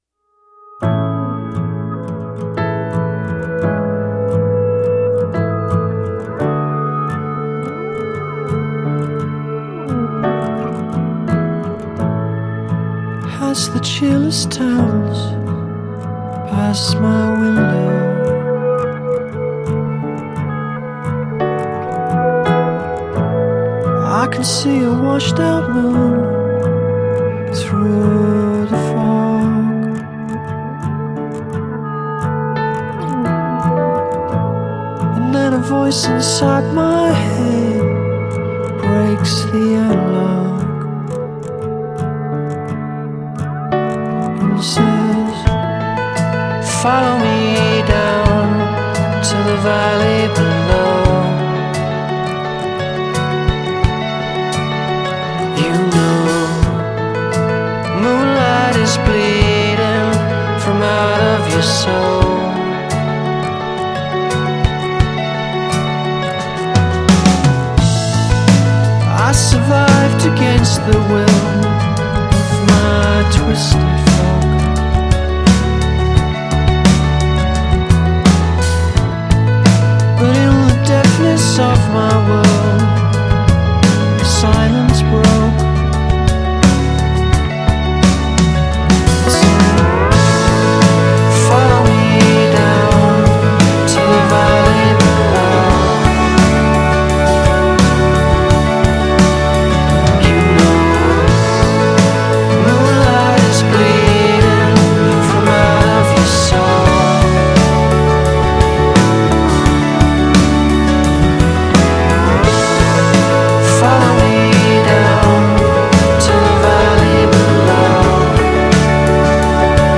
in one of that band’s lovely mellow moments.